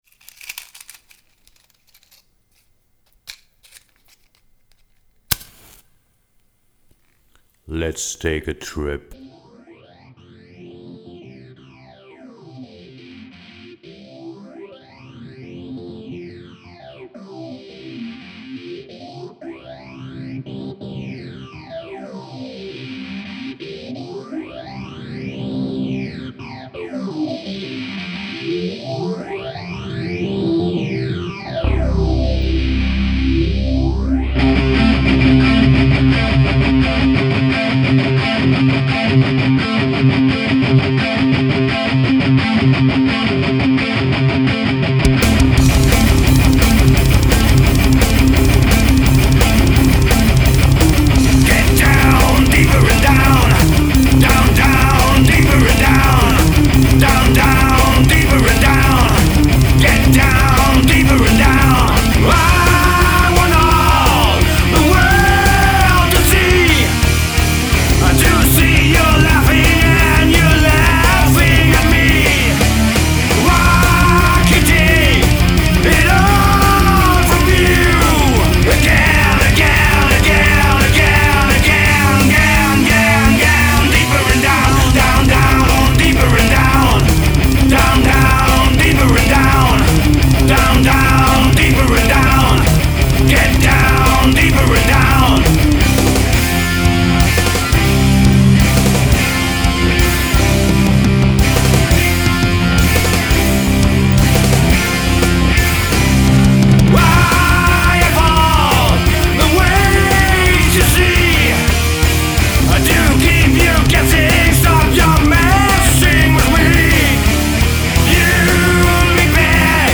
Stil : Metal?